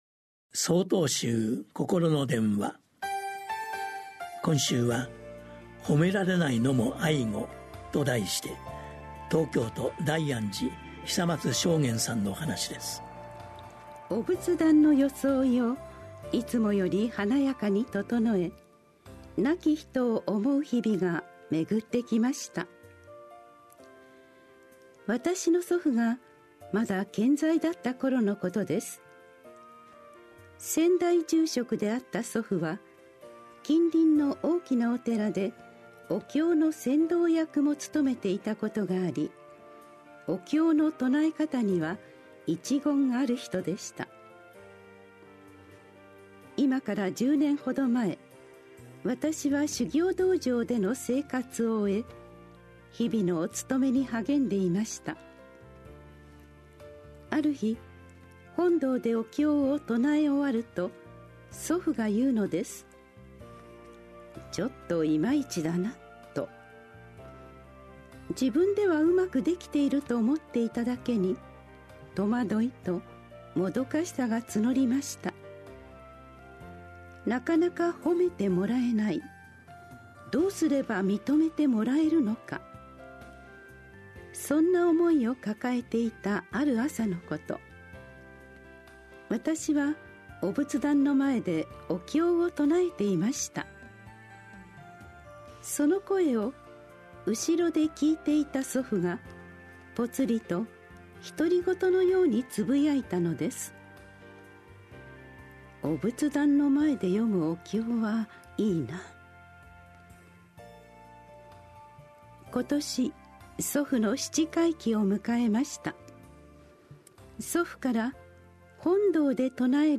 心の電話（テレホン法話）８/12公開『誉められないのも愛語。』